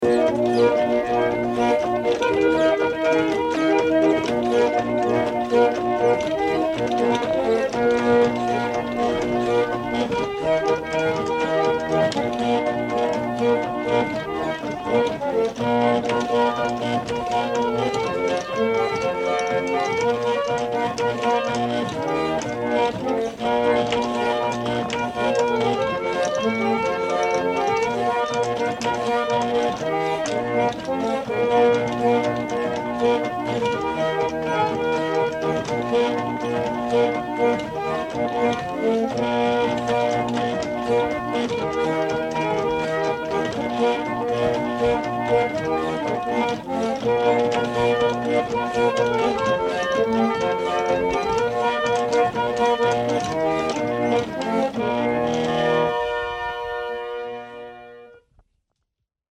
Princess Reel (pump organ solo